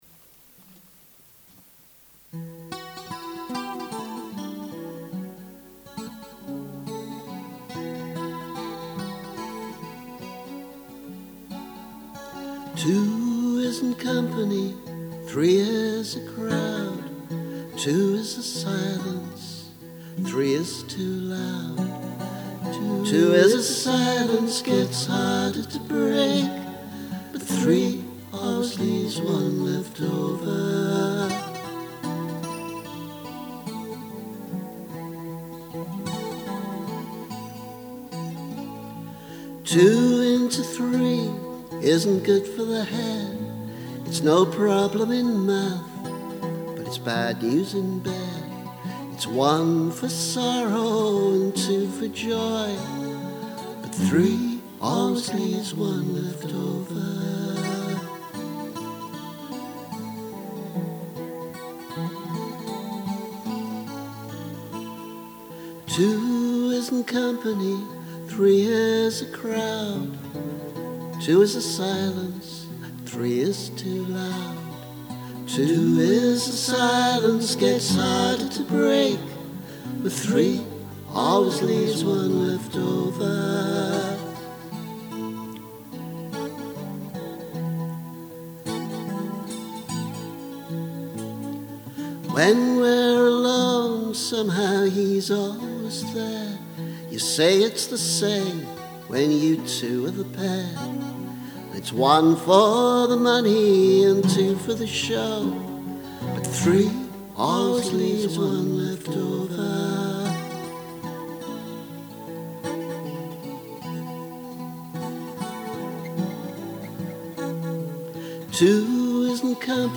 Two is a silence [Demo]